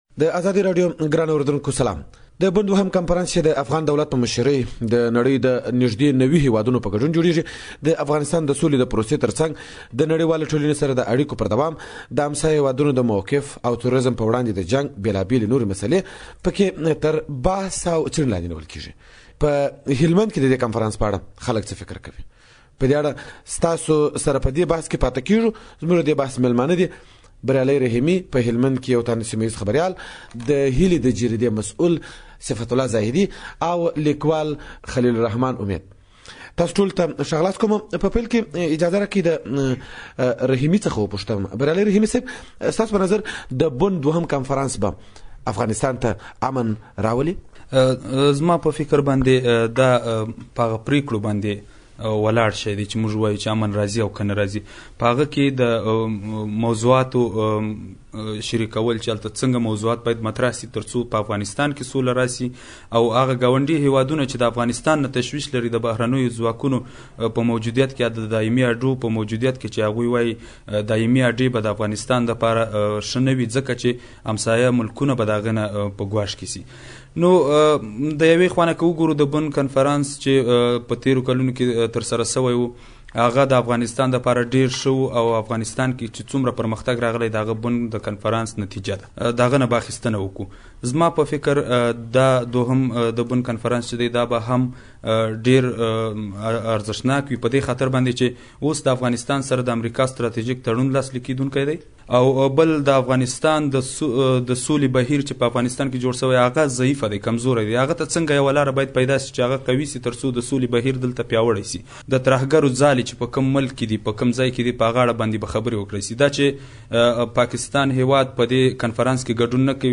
له هلمند څخه برابر شوى بحث